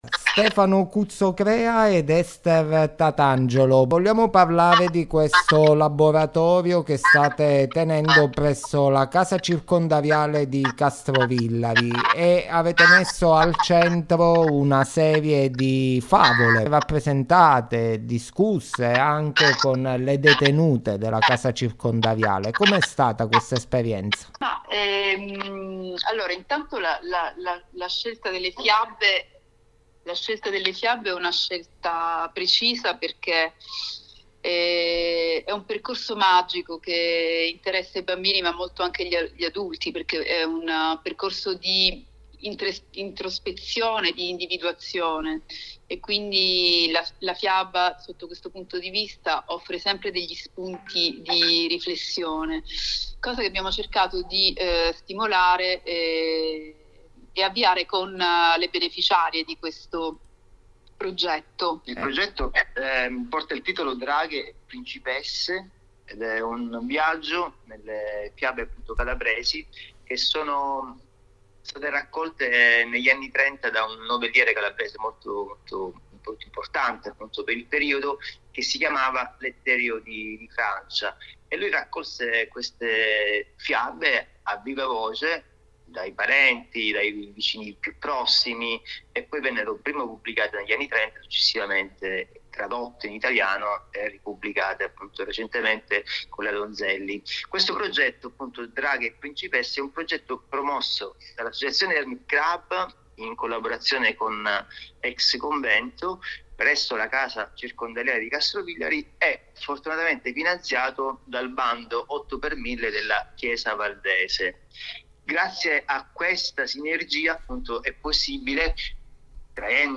abbiamo intervistato telefonicamente